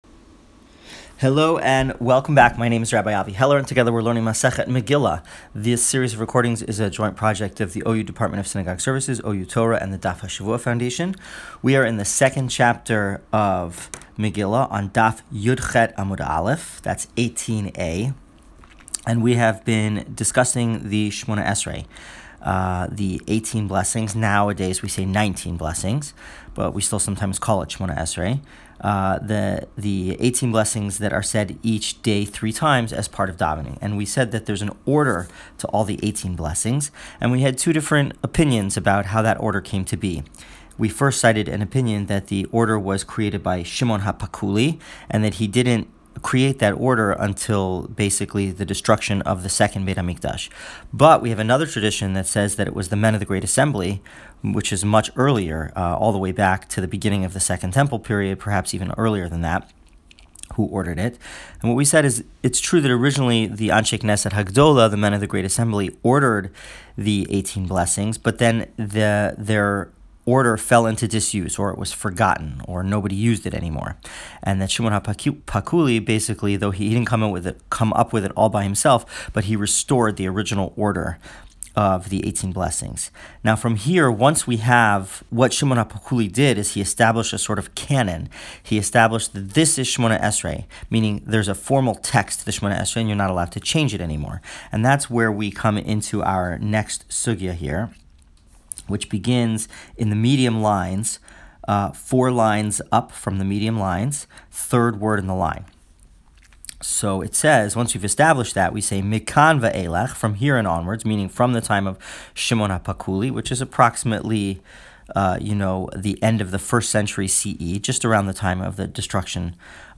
Shiur: Megillah 18a part 1